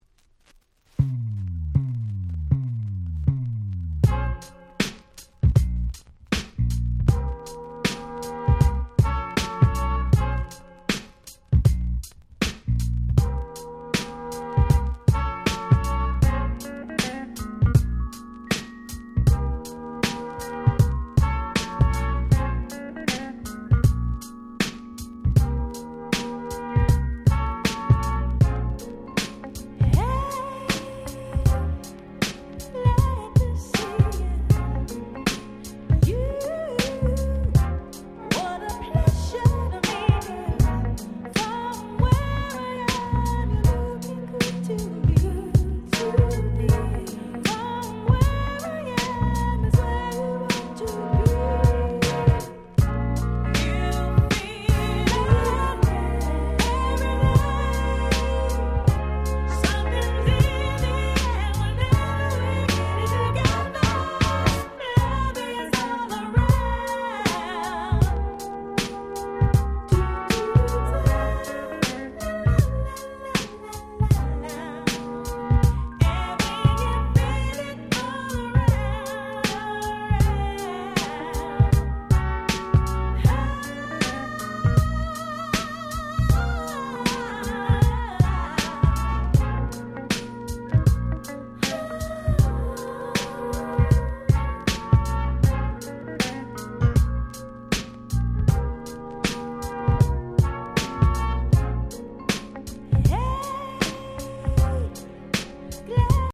97' Nice R&B/Neo Soul !!